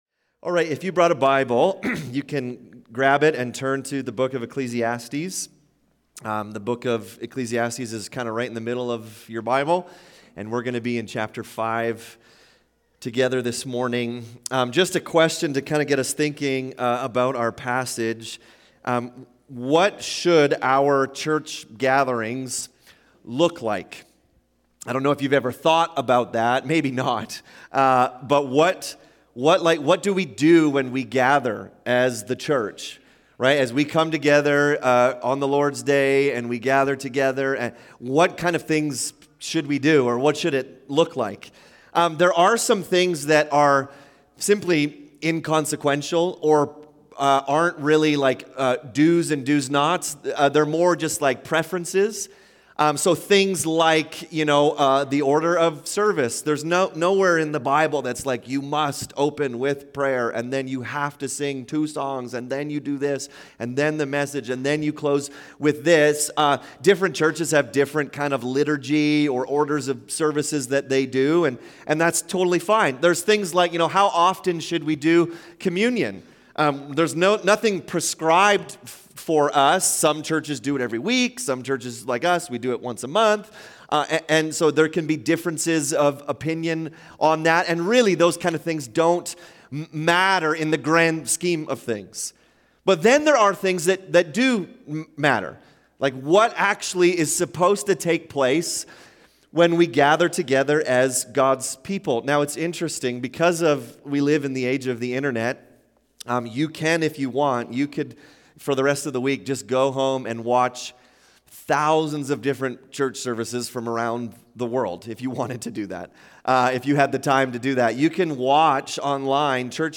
Sermons | North Peace MB Church